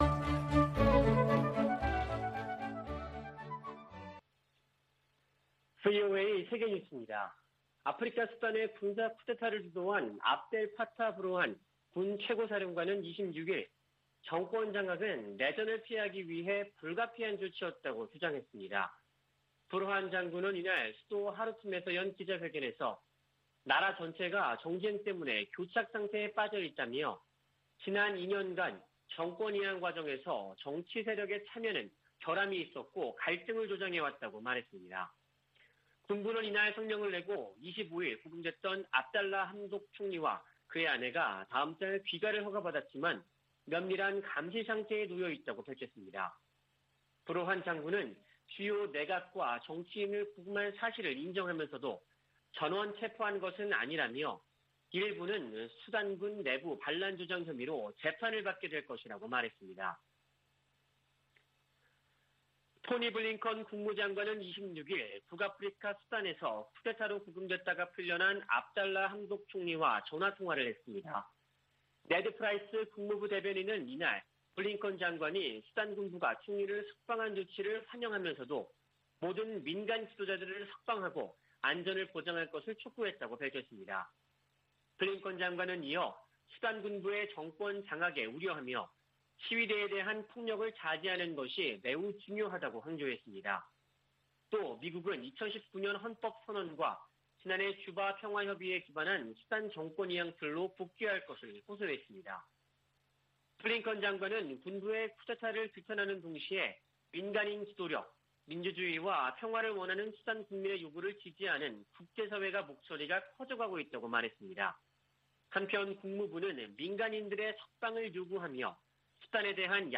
VOA 한국어 아침 뉴스 프로그램 '워싱턴 뉴스 광장' 2021년 10월 28일 방송입니다. 대북 접근법 수행 과정에 미국과 한국의 관점이 다를 수도 있다고 제이크 설리번 미 국가안보 보좌관이 밝혔습니다. 미 국무부가 국제 해킹 대응 조직을 신설합니다. 캐나다 인권단체가 한국과 미국에 이어 세 번째로 제3국 내 탈북 난민 수용 시범 프로그램을 시작합니다.